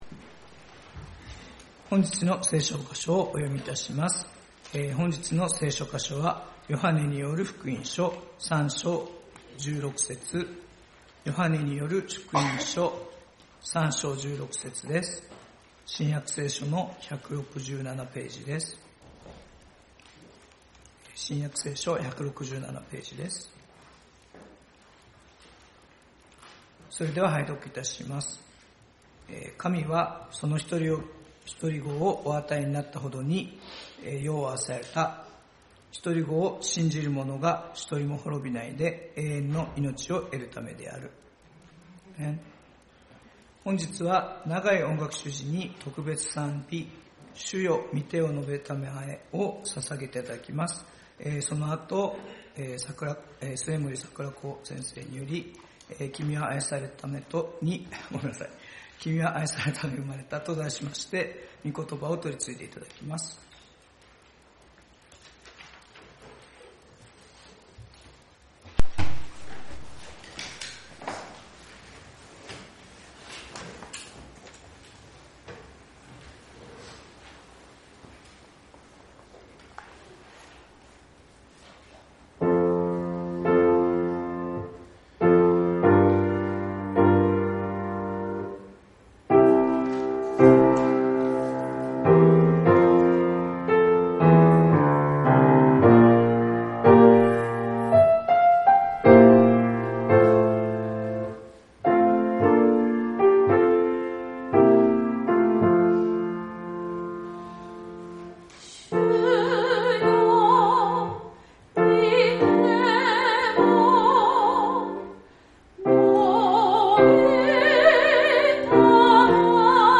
Welcome Worship礼拝「きみは愛されるために生まれた」